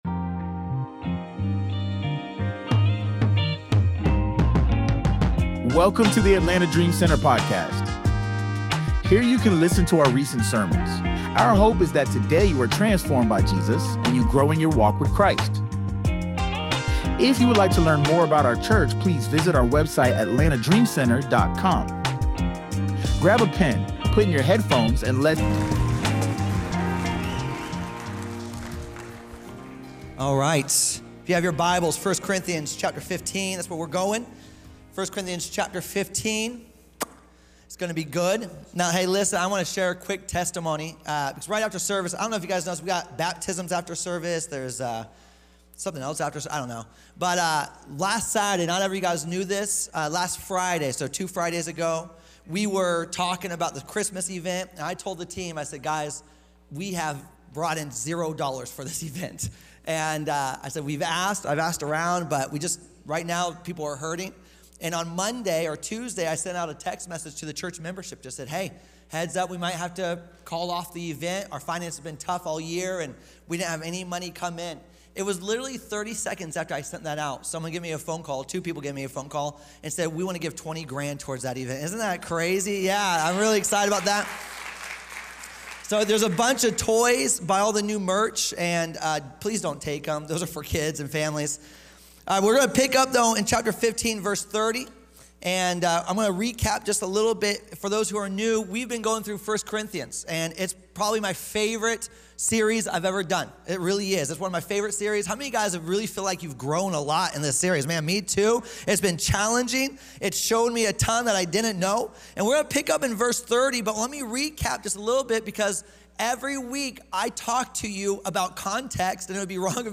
let's talk about our hope in the resurrection of our bodies and receiving a heavenly body like Christ! sermon found in 1 cor. 15